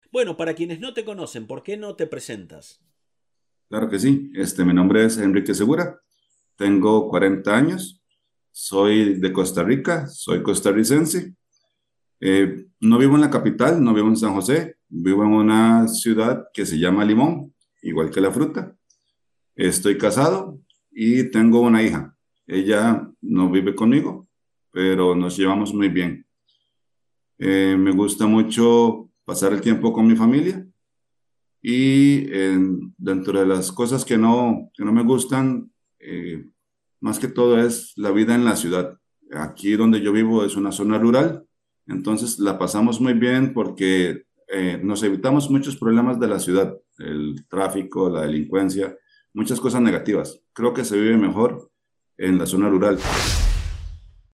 interview-introducing yourself – Spanish Like a Pro!
UNSCRIPTED SPANISH 1/9
ESPAÑOL DE COSTA RICA